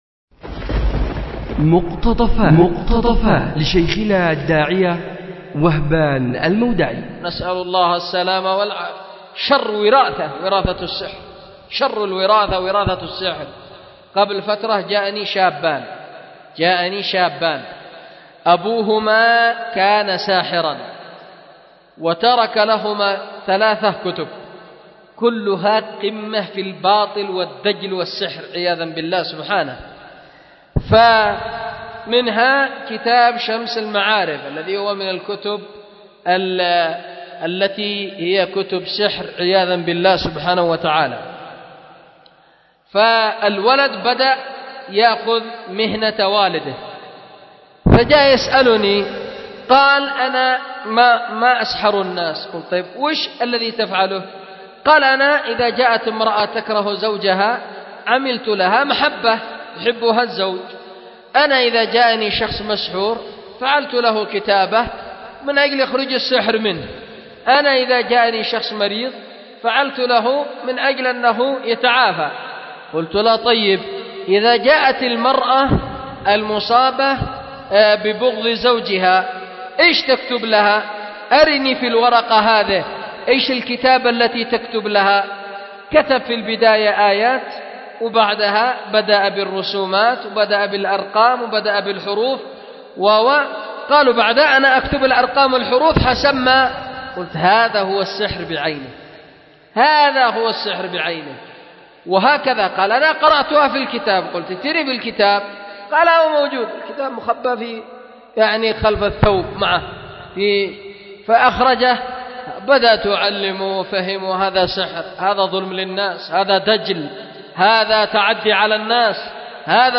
مقتطف من درس
أُلقي بدار الحديث للعلوم الشرعية بمسجد ذي النورين ـ اليمن ـ ذمار